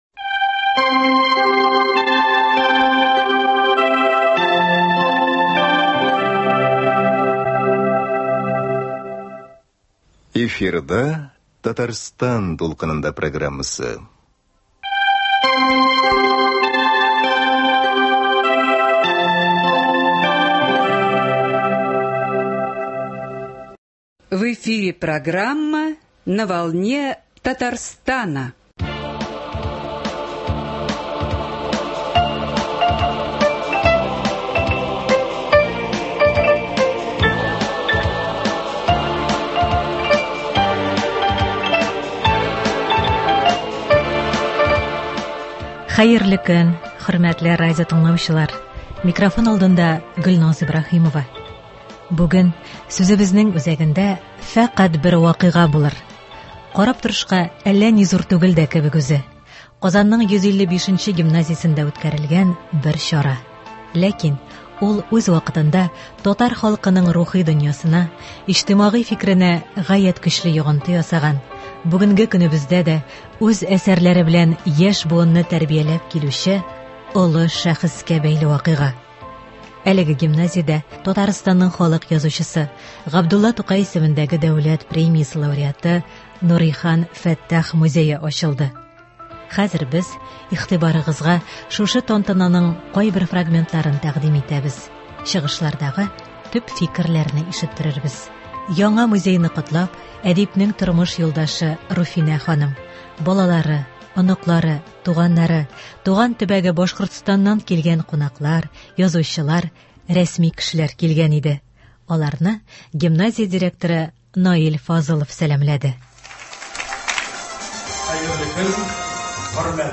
Татарстанның халык язучысы Нурихан Фәттах музеен ачу чарасыннан репортаж.
Карап торышка әллә ни зур түгел дә кебек үзе – Казанның 155 нче гимназиясендә үткәрелгән бер чара.
Хәзер без игътибарыгызга шушы тантананың кайбер фрагментларын тәкъдим итәбез, чыгышлардагы төп фикерләрне ишеттерербез.